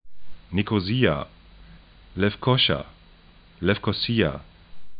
niko'zi:a